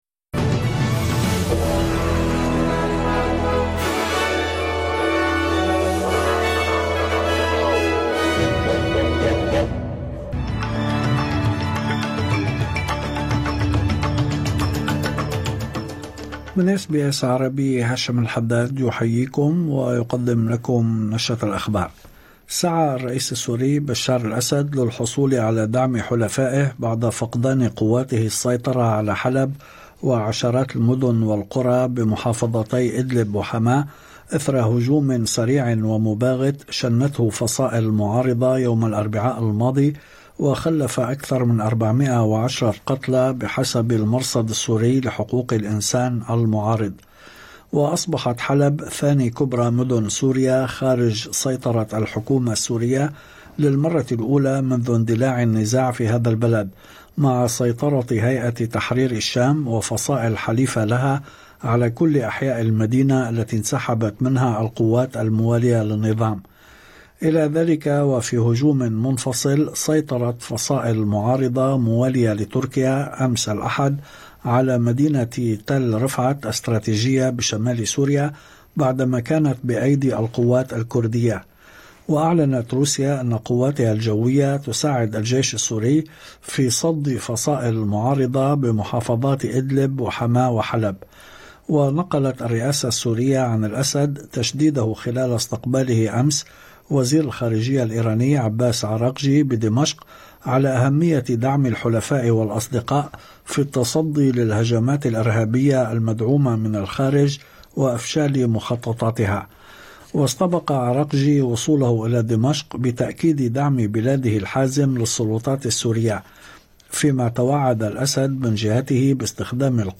نشرة أخبار الظهيرة 2/12/2024